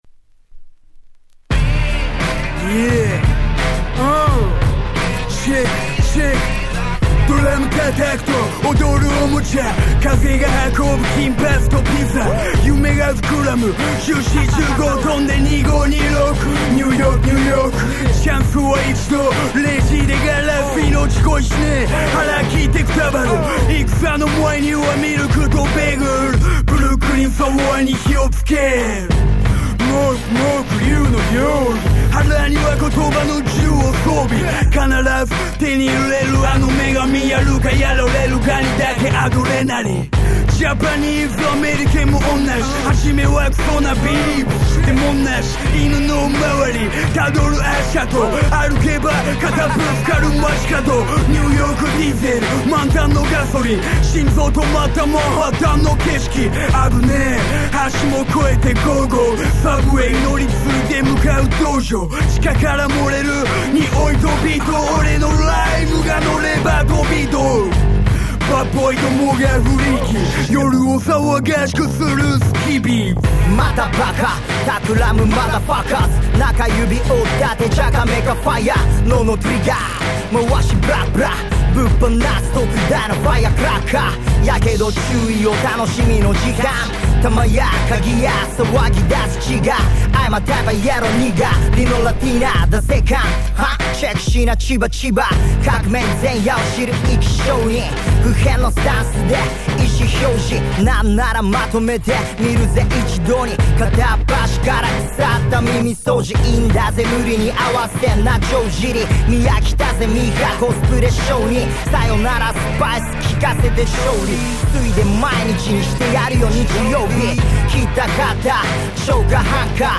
10' Super Nice Japanese Hip Hop !!
頭を振らずにはいられない、そんな男汁が溢れかえる1曲です！！